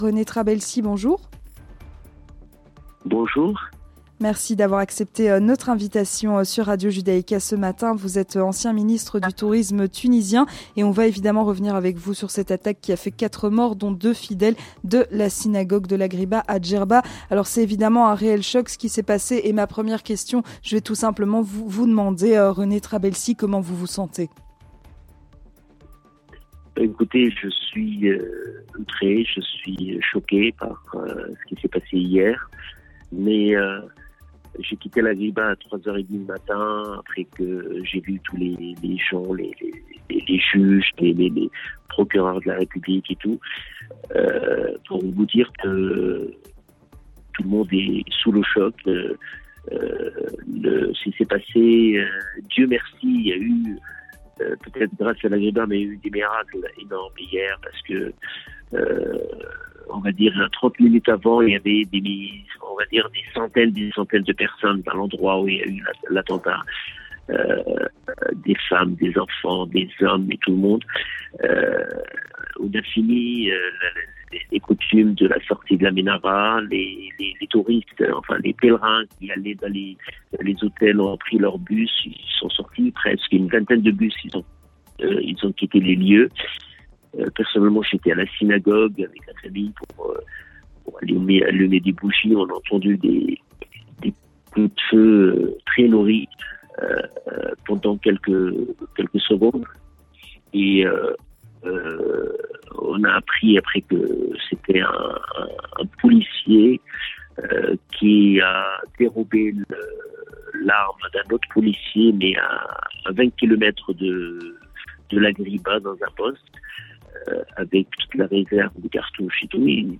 Rencontre avec René Trabelsi, ancien ministre du tourisme en Tuinisie (10/05/2023)